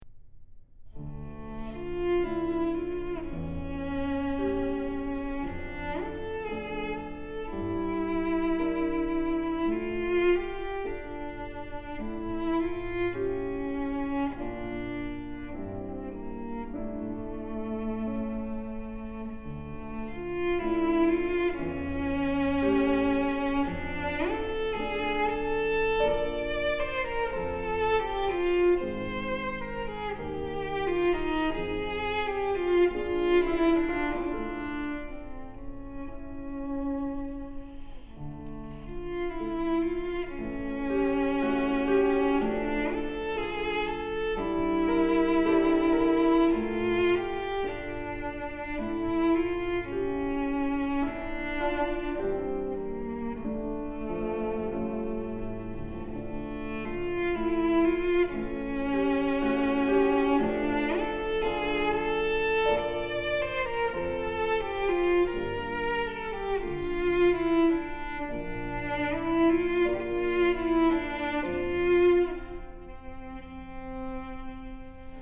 ☆才華洋溢、沈穩內斂的大師級演奏風範，不必刻意炫技卻更教人心動！
☆極簡錄音處理，再現最真實質樸且極具典雅氣質的樂器溫潤光澤。